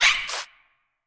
Sfx_creature_babypenguin_flinch_land_03.ogg